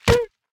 Minecraft Version Minecraft Version 1.21.5 Latest Release | Latest Snapshot 1.21.5 / assets / minecraft / sounds / enchant / thorns / hit3.ogg Compare With Compare With Latest Release | Latest Snapshot